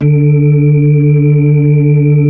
Index of /90_sSampleCDs/Roland LCDP10 Keys of the 60s and 70s 2/B-3_Brite Fast L/B-3_Brite Fast L